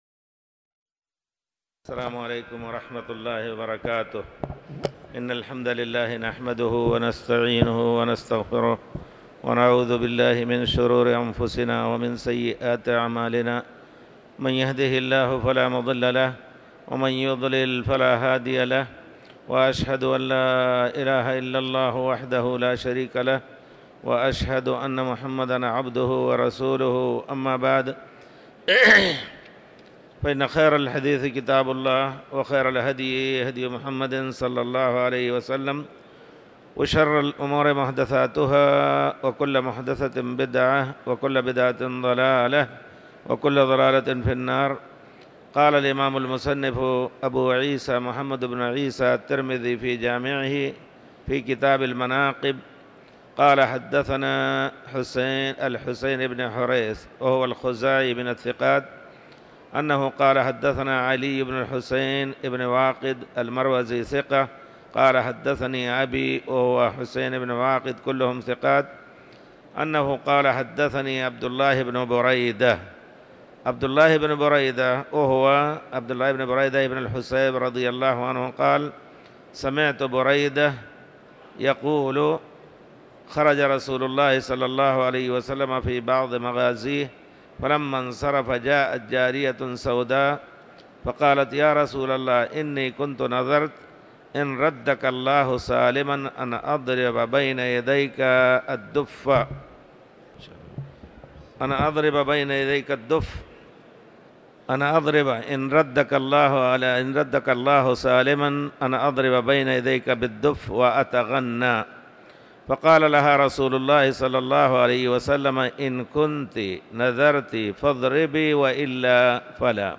تاريخ النشر ٩ رجب ١٤٤٠ هـ المكان: المسجد الحرام الشيخ